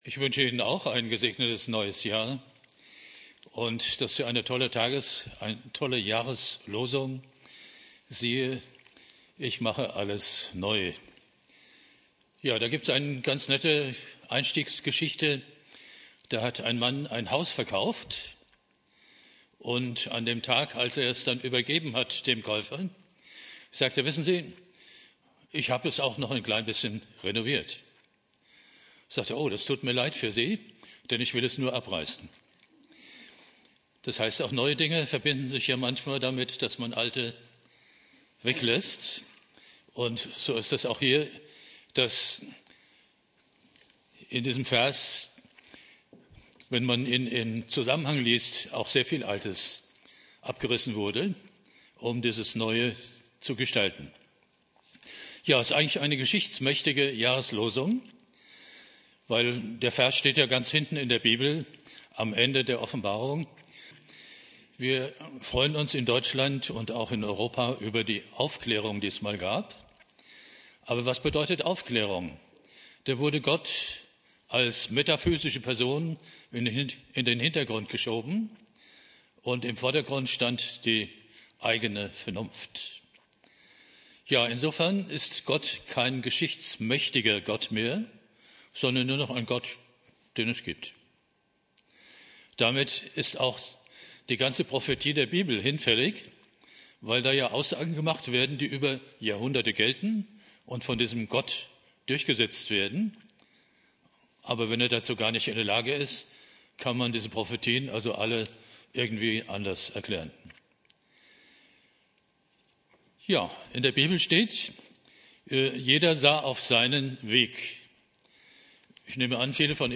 Predigt
Neujahr Prediger